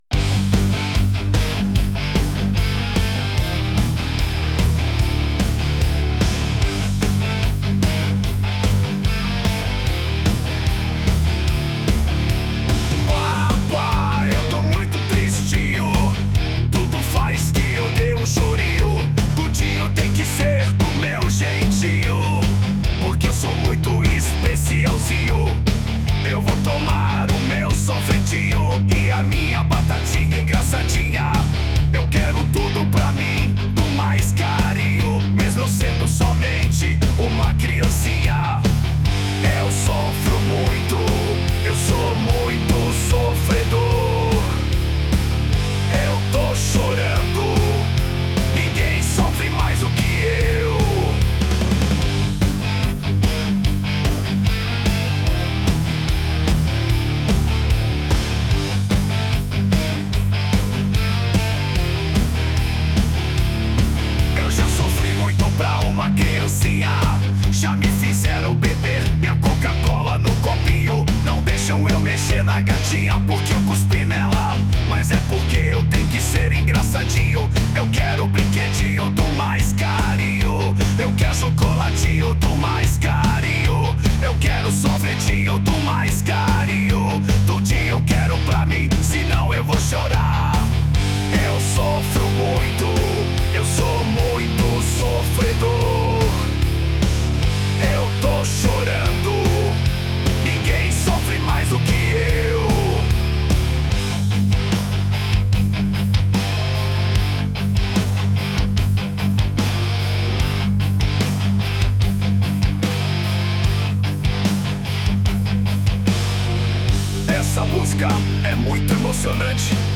Versão Metal 3